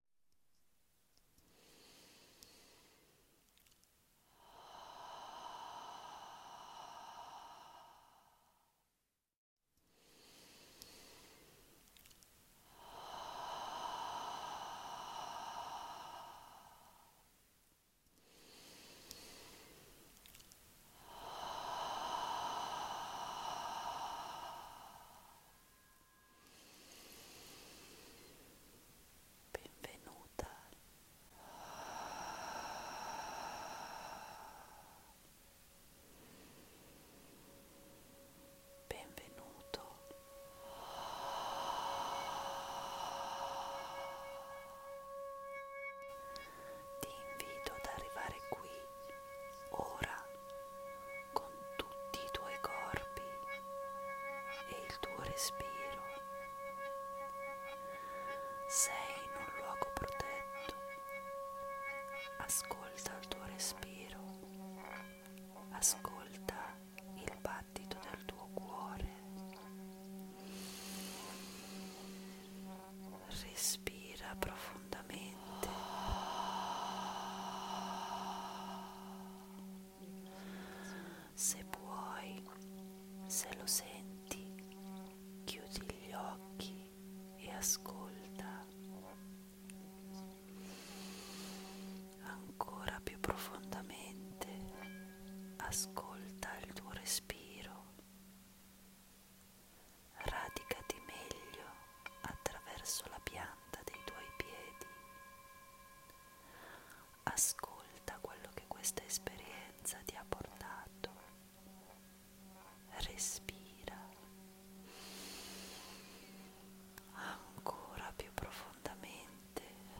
Audio Meditation